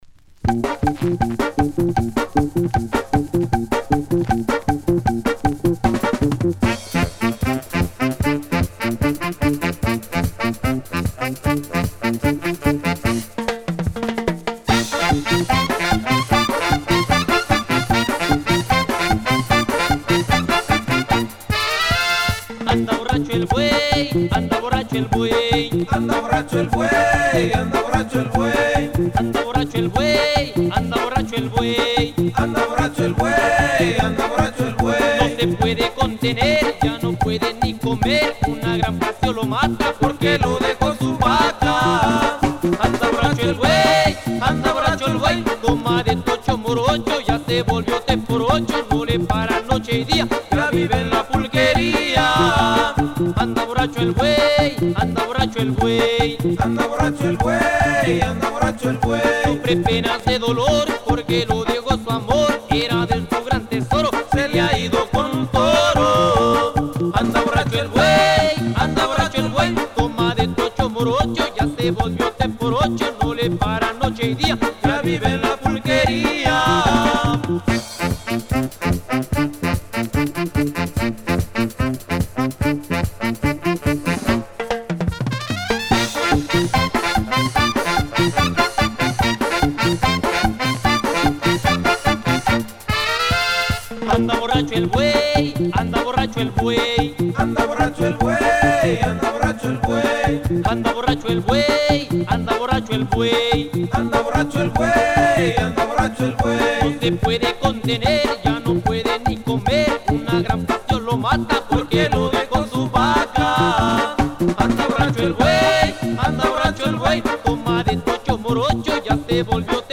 super fast track
which has a less techno sound than the first.